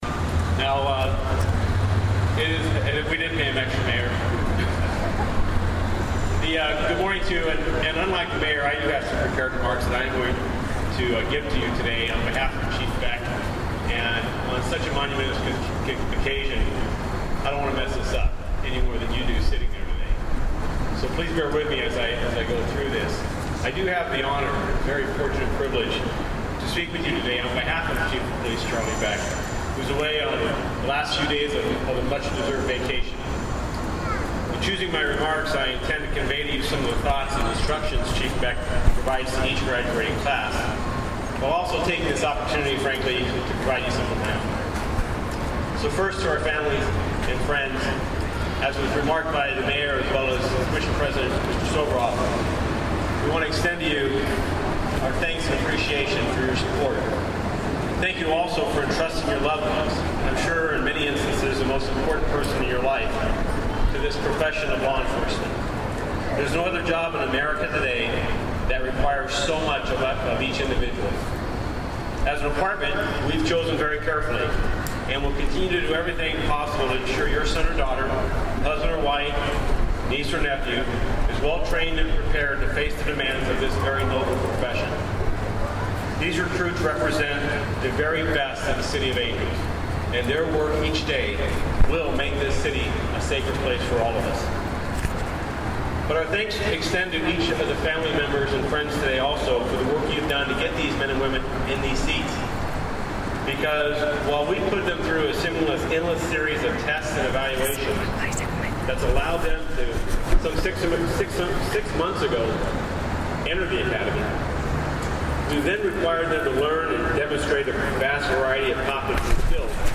The Los Angeles Police Department graduated 20 police officers on Friday, September 2, 2016.  The ceremony took place at the Police Headquarters Facility.
More than 500 guests attended the graduation ceremony.  Assistant Chief Michel Moore inspected and gave the class a warm welcome on behalf of Chief of Police Charlie Beck.